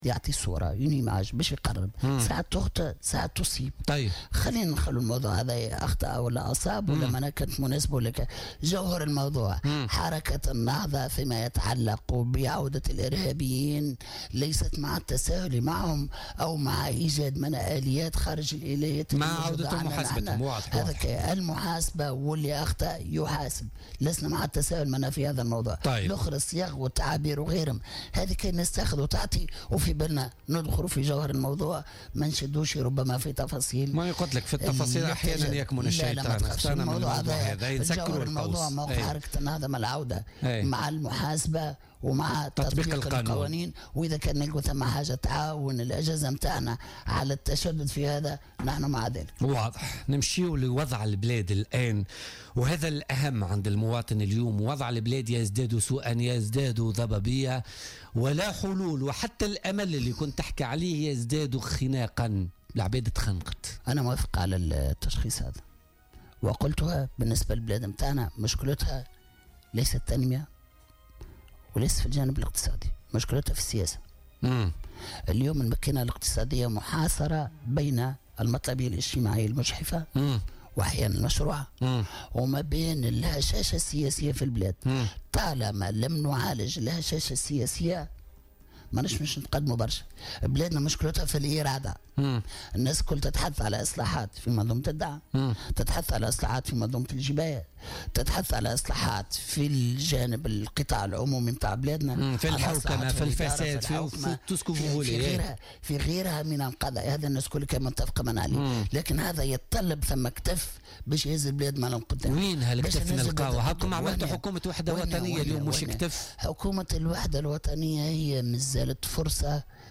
وأكد القيادي بحركة النهضة عبد الحميد الجلاصي ضيف بولتيكا اليوم الإثنين 9 جانفي 2017 أن مشكلة البلاد ليست في التنمية ولا في الجانب الاقتصادي بل مشكلتها سياسية بحتة .